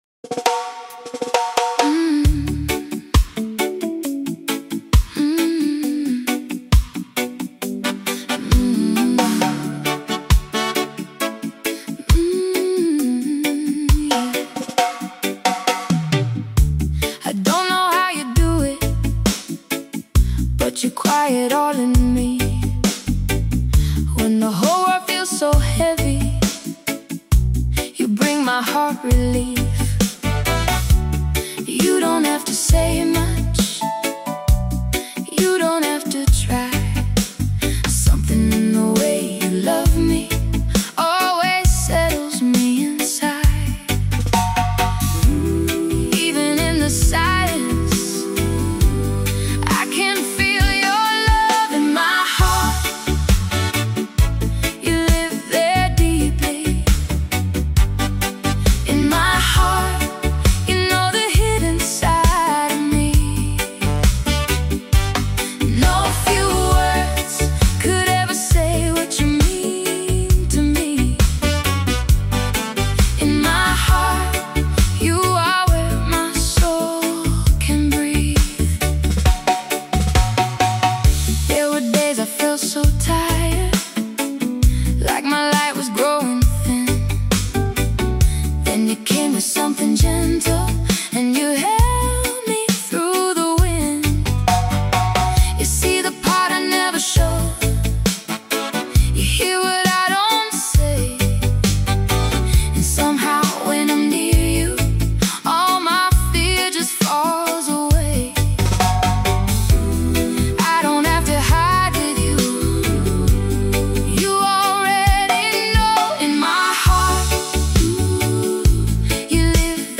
There is a quiet pull to this song that builds gradually.